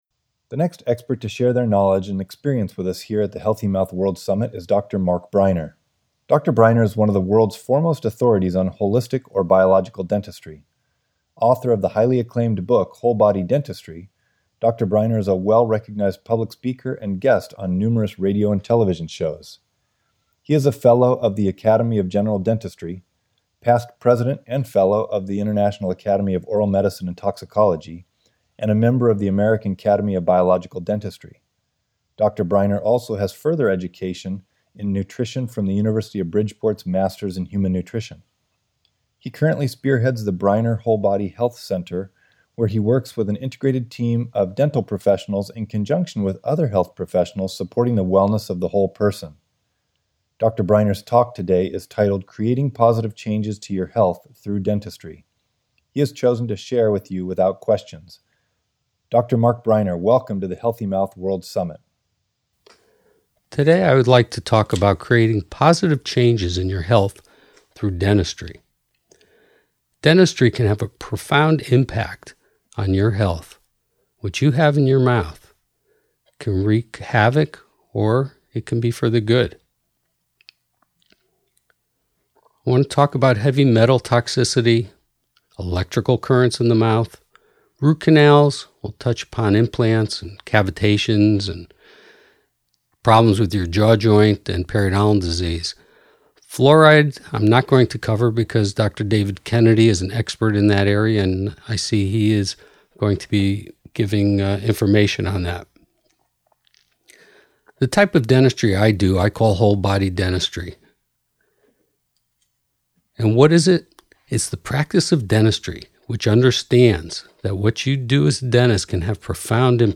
Expert Interview: Creating Positive Changes in Your Health